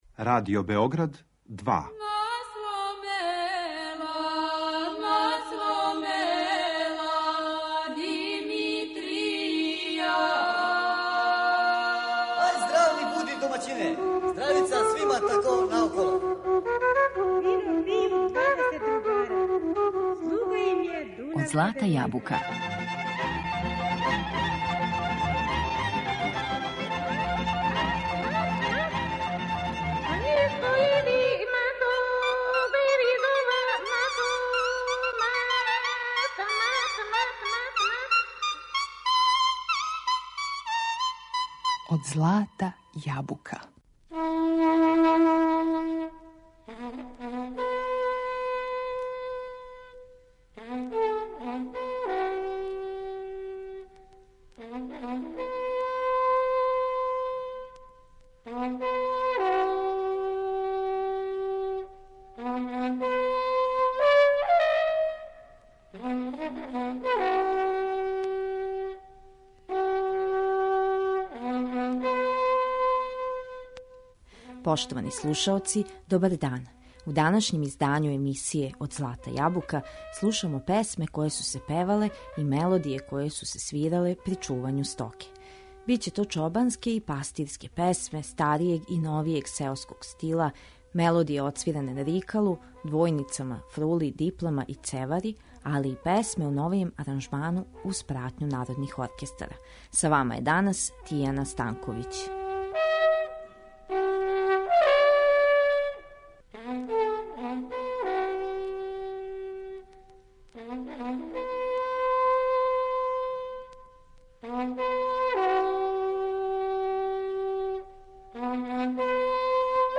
Биће то чобанске и пастирске песме старијег и новијег сеоског стила, мелодије одсвиране на рикалу, двојницама, фрули, диплама и цевари али и песме у новијем аранжману уз пратњу народних оркестара.